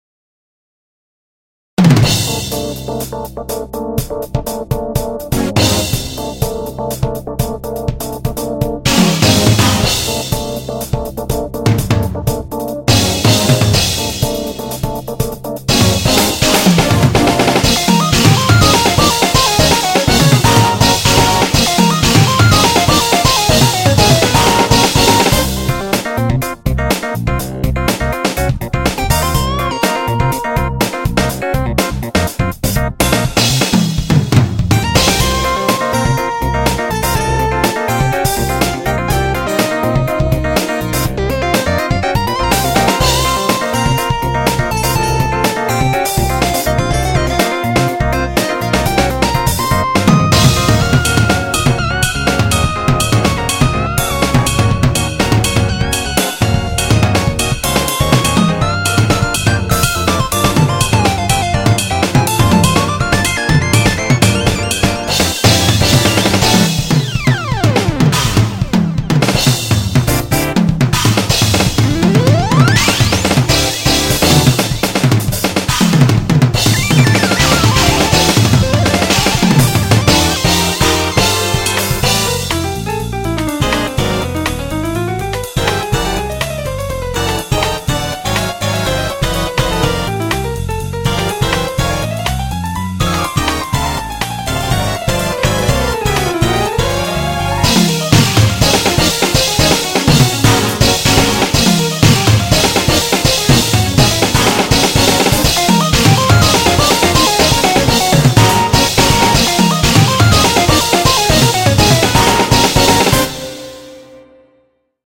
FUTURE JAZZ
프로그래밍으로 만들어진 것 치고는 테크닉이 대단 합니다(?)